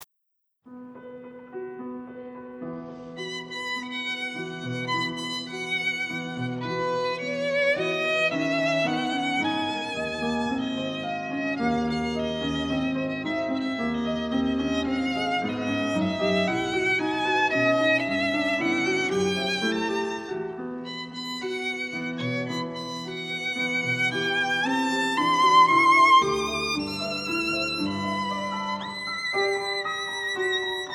Violin
in concert and in studio